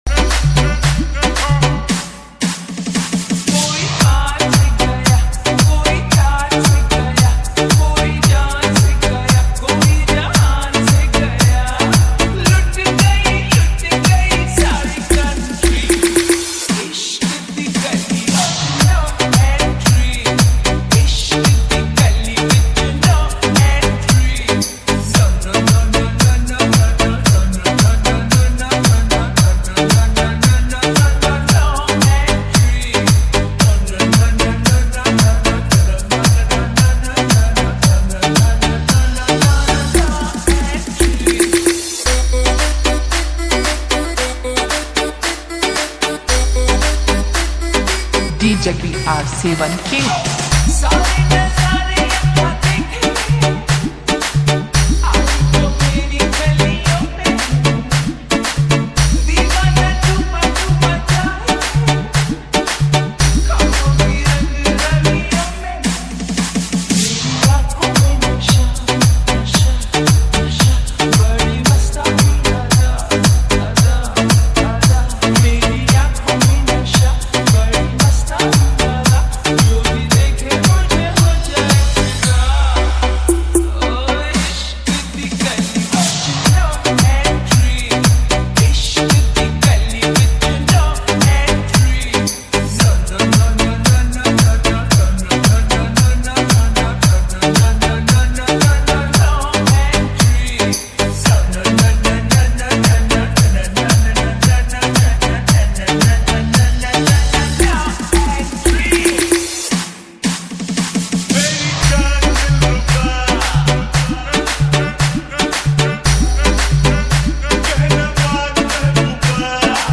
bass boostedmusic